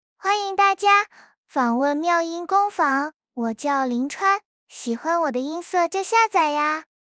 林川 少年/幼态/正太音少年感 RVC模型
看见妙音的正太音模型很少，这次训练了一个少年和幼态感十足的正太音，介绍一下为什么会有两种感觉，假设你是女生，当你说话便温柔的时候，输出的音色就是会比较幼，当你原始音色稍微偏御一些，输出的音色就会是那种少年正太音，我们在数据集中收录了许多正太音色最终经过后期处理呈现出该模型。
幼态未推理